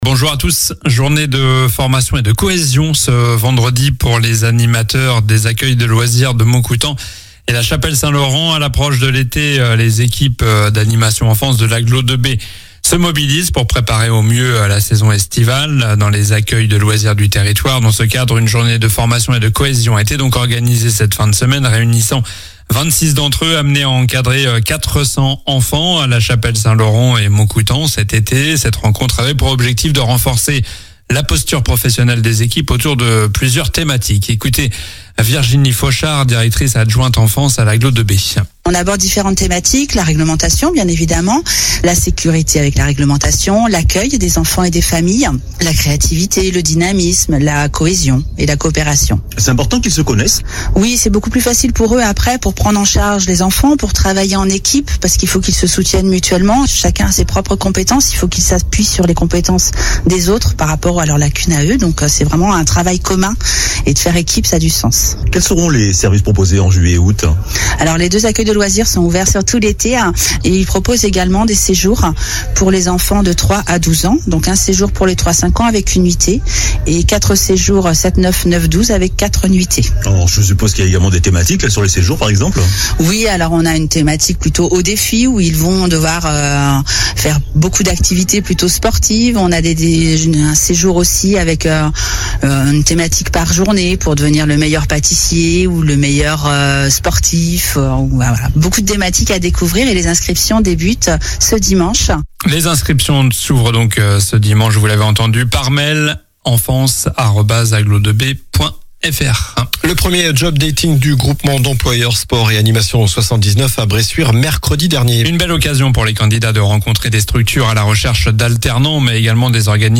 Journal du samedi 3 mai (matin)